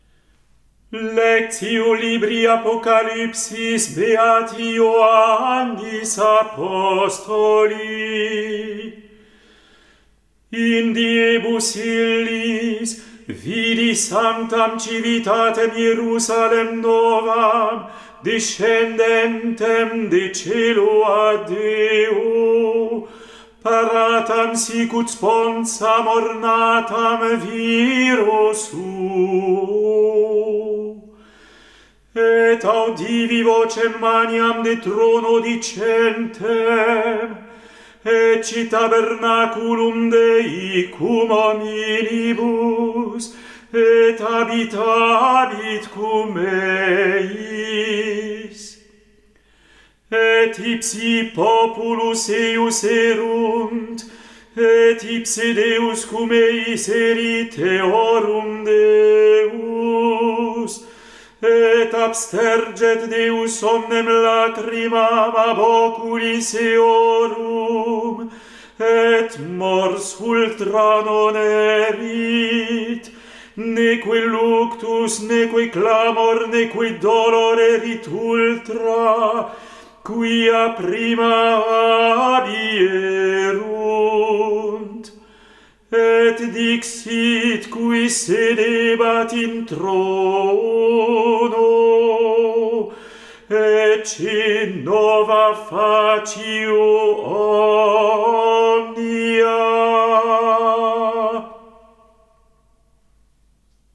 Epistola